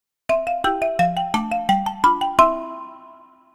HappyMarimba.ogg